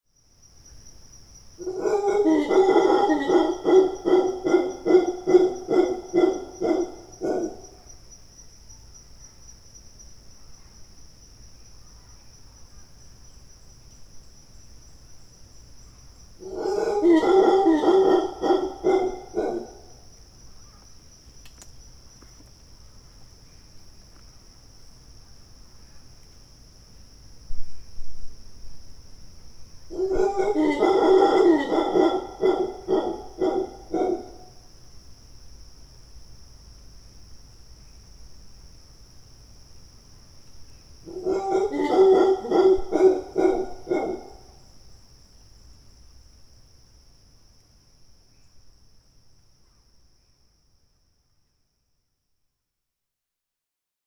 „Sempervirent“ is a stereo edit of a series of ’sequence-shots‘ where the quadraphonic microphone setup records all that passes by.
03 the call, the gathering, the storm – mantled howler monkey, orange-fronted parakeet, white-fronted parrot, yellow-naped amazon – 11’43
howler_monkeys_excerpt.mp3